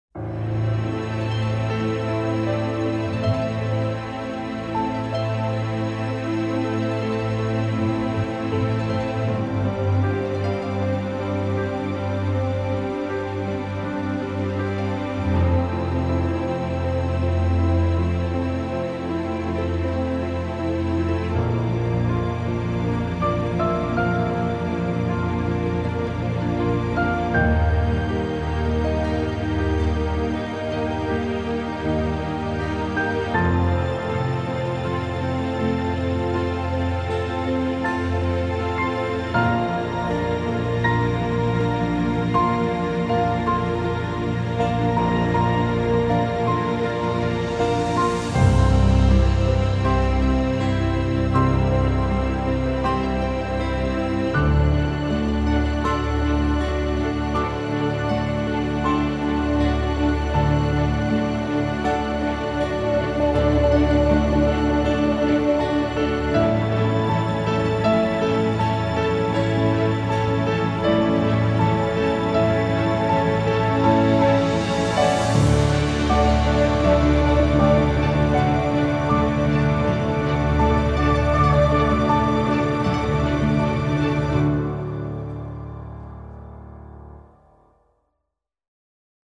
ethnique - profondeurs - epique - flute de pan - perou